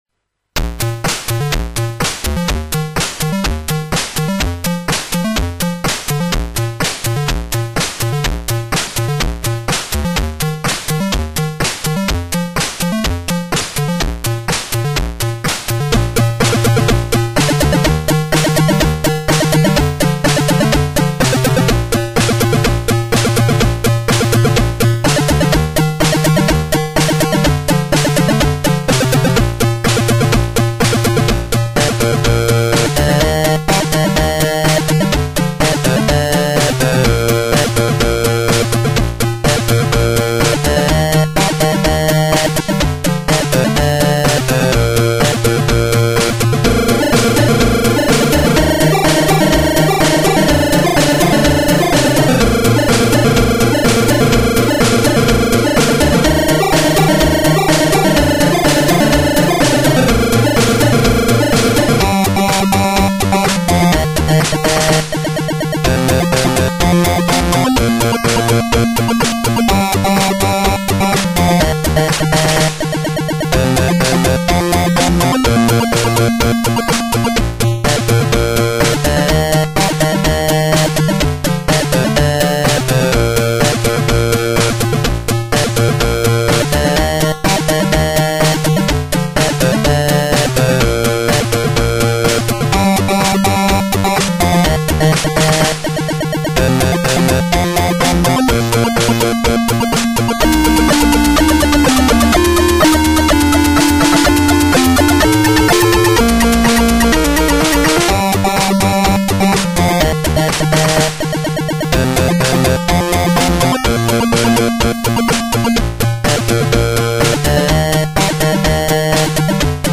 All sounds recorded using line-in of PC.
Sound Example 2 (Emulator version 10.4 3 channel surround 27MHz)